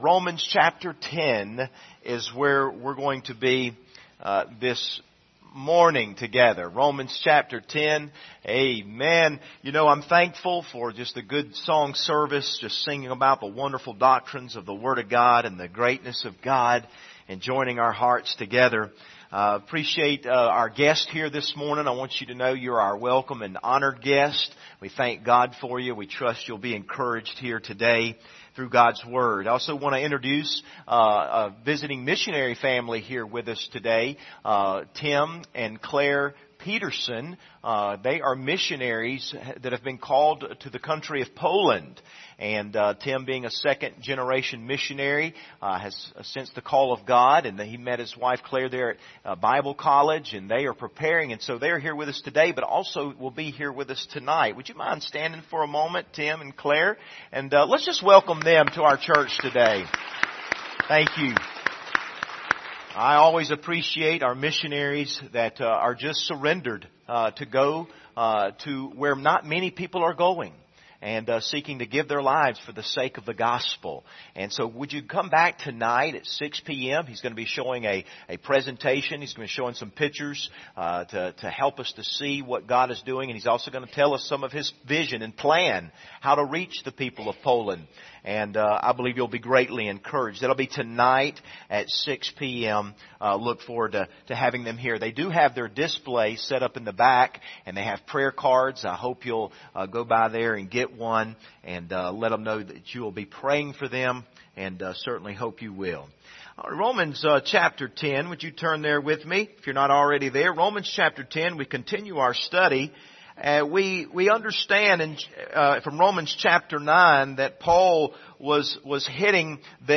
Passage: Romans 10:1-13 Service Type: Sunday Morning